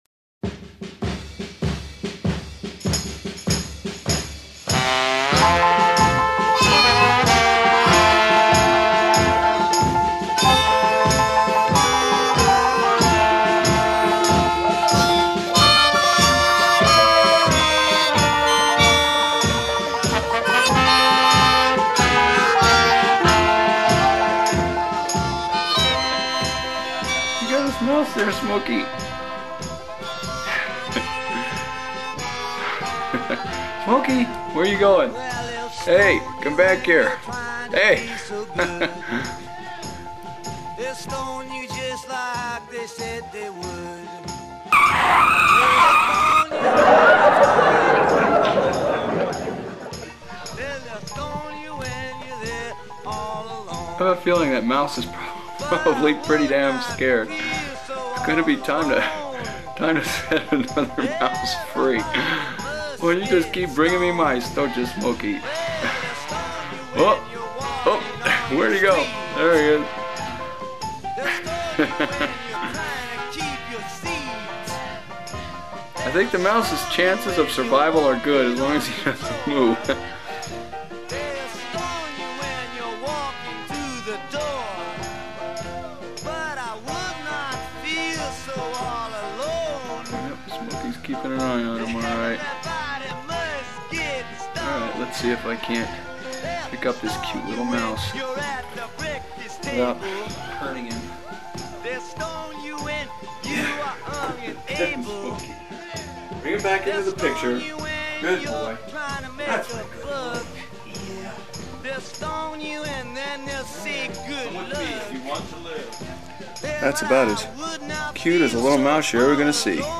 The second video was taken in my livingroom.
I was doing some work in my office and heard Smokey's tell-tale meow.